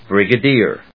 音節brig・a・dier 発音記号・読み方
/brìgədíɚ(米国英語), brìgədíə(英国英語)/